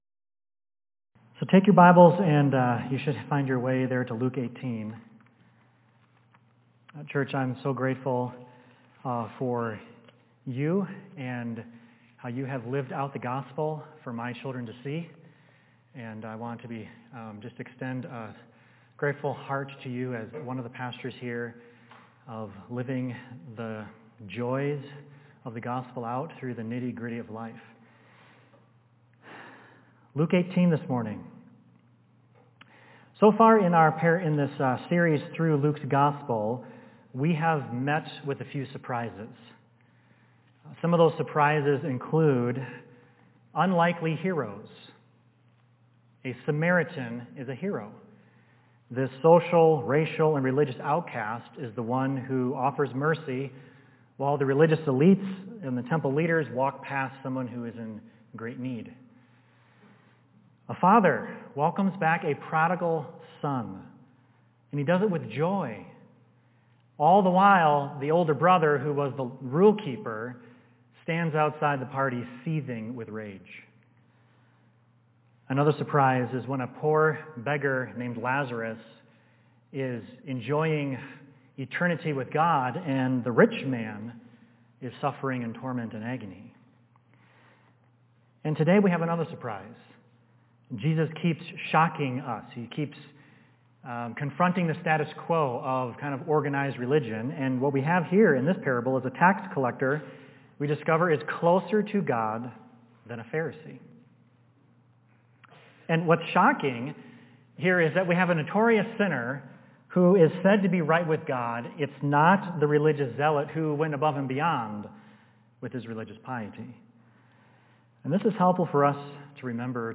Sermons
Service: Sunday Morning